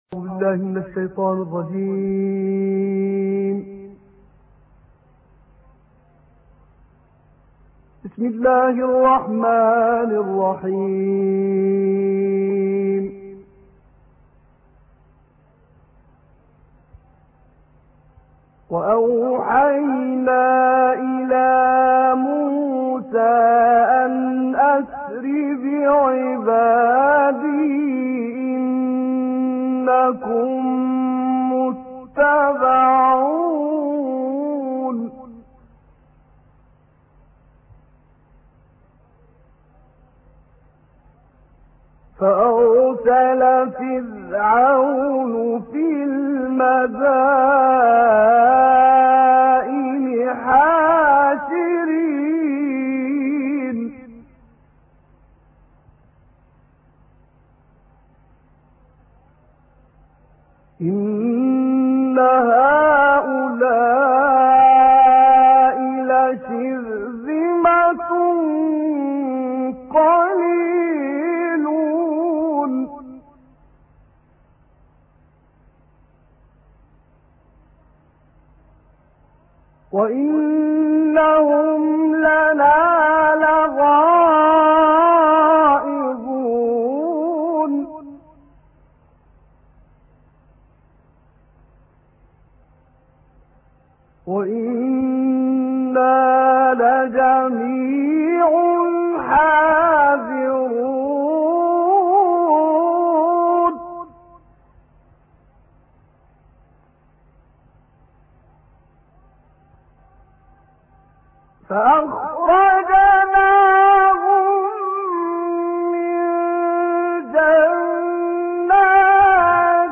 تلاوتهای قاریان مصری با «تصدیق شیعی»